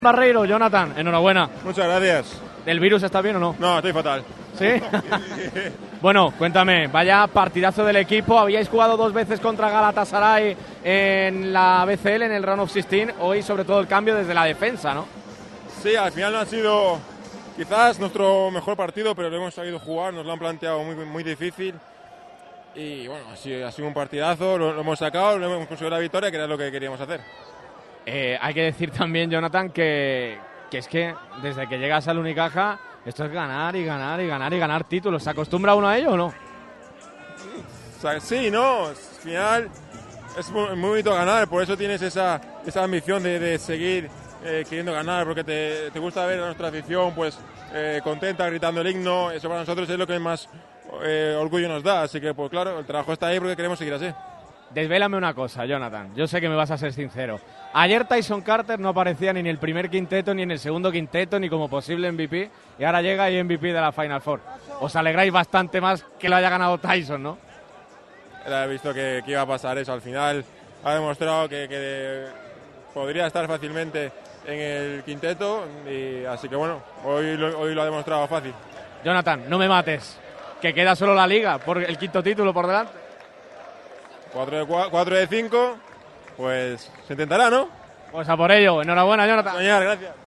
Las reacciones de los campeones de la BCL sobre el parqué del Sunel Arena.
JONATHAN BARREIRO, JUGADOR